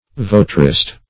Search Result for " votarist" : The Collaborative International Dictionary of English v.0.48: Votarist \Vo"ta*rist\, n. [See Votary .]